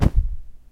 fall.ogg